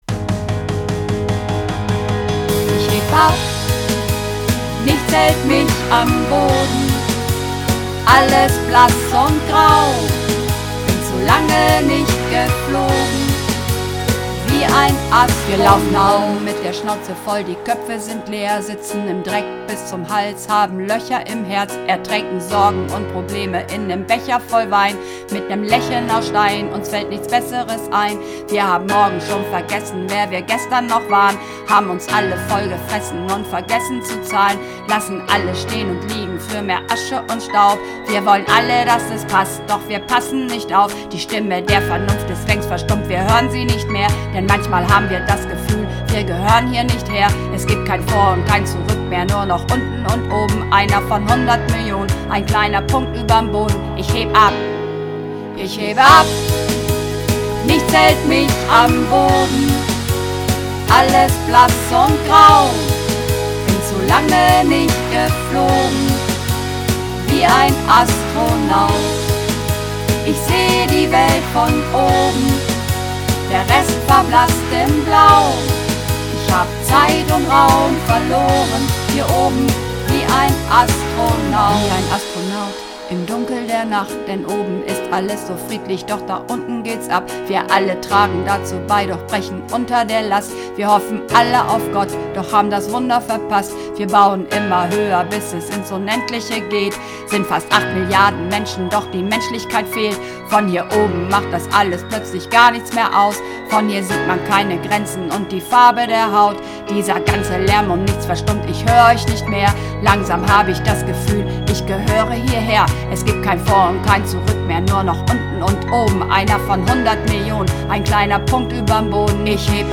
(Mehrstimmig)